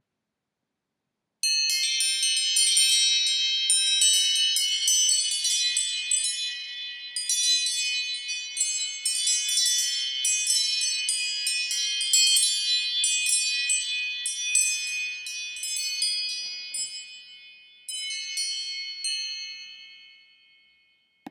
8. Chimes
Though loud enough to rouse deep sleepers without hitting the snooze button, Chimes isn’t too jolting. You’ll feel like you’re waking up in a Zen garden — until you realize you’re still in bed.
Chimes.m4a